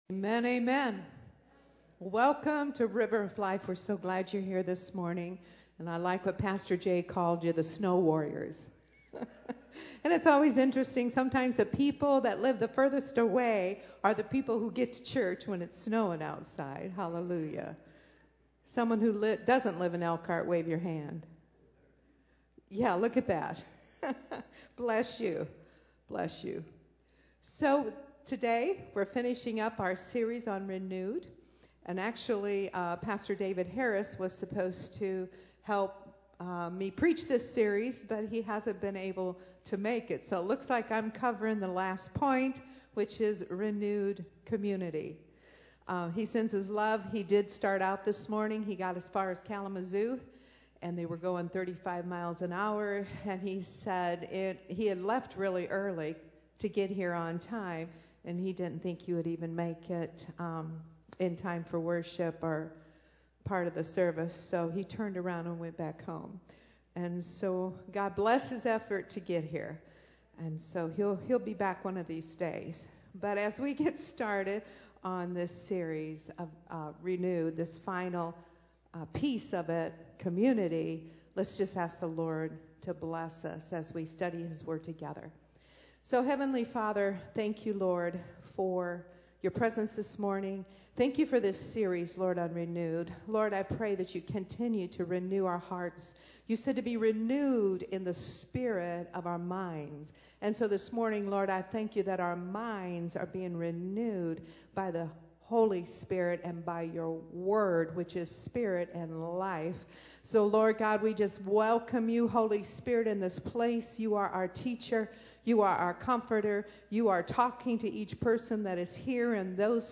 Sermons Archive - River of Life Community Church